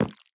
Minecraft Version Minecraft Version latest Latest Release | Latest Snapshot latest / assets / minecraft / sounds / block / sign / waxed_interact_fail1.ogg Compare With Compare With Latest Release | Latest Snapshot
waxed_interact_fail1.ogg